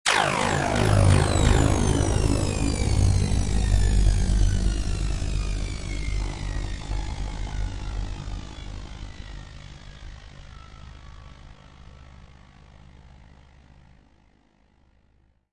太空小说的声音效果 " 06616 scifi plant drone
描述：未来派植物无人机的氛围
Tag: 未来 音景 氛围 环境 背景 科幻 无人驾驶飞机 阴暗 气氛 科幻 噪声 工厂